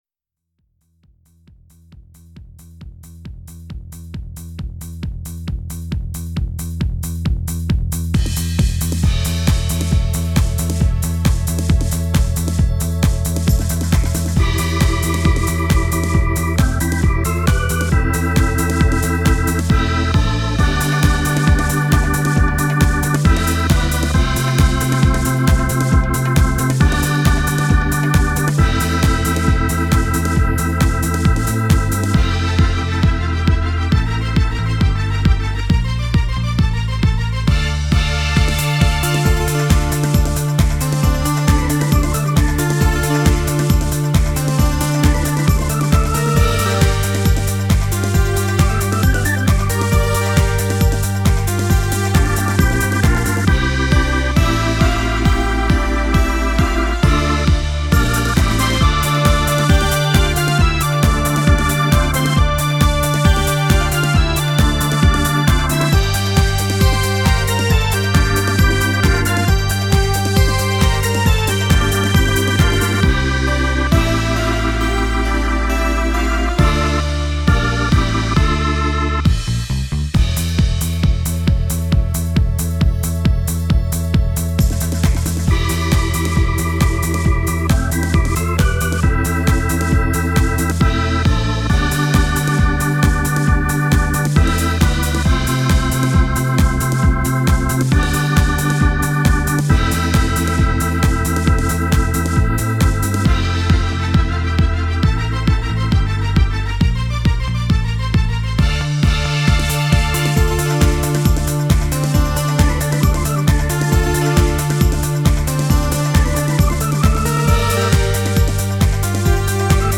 Ηλεκτρική Κιθάρα
Ακουστική Κιθάρα